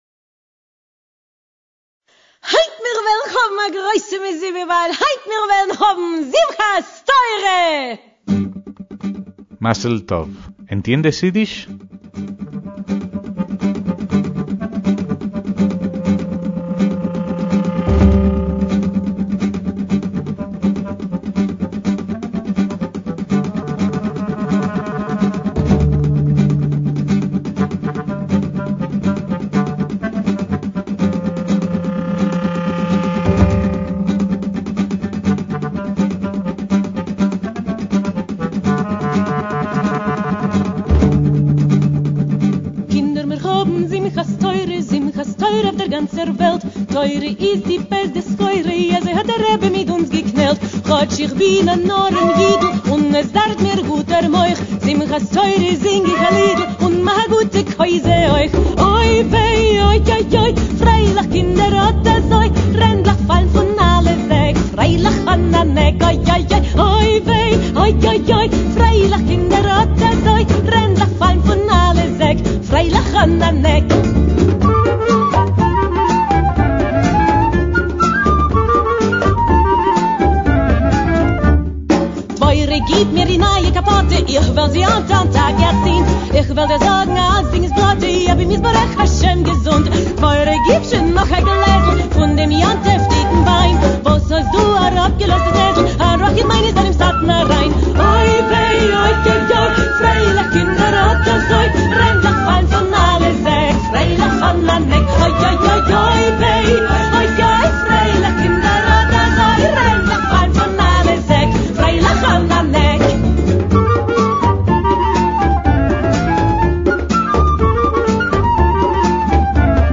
MÚSICA ÍDISH
clarinete
contrabajo
guitarra y percusiones
flauta
guitarra y armónica